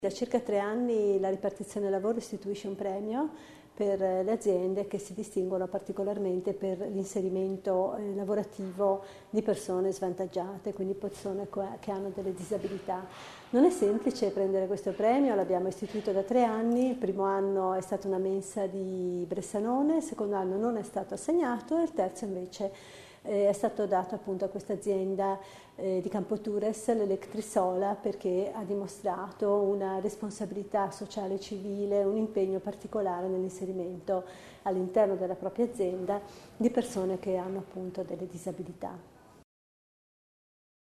L'Assessore Repetto illustra le finalità del premio